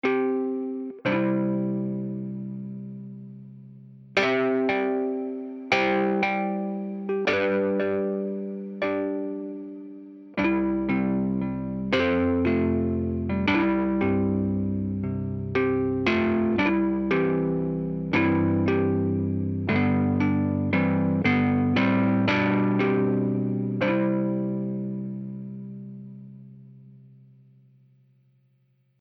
Das nächste E-Piano der Library ist das Claviset des ostdeutschen Herstellers Weltmeister, der vor allem durch seine Akkordions bekannt geworden ist.
Verschiedene Varianten bieten reine, saubere und brave bis zu bissige und etwas scheppernde Klänge. Hier die Version „Trashy 2“:
Man hört sogar das Geräusch der rotierenden Rädchen im Hintergrund. Das Pattern stammt aus Toontrack EZkeys Country.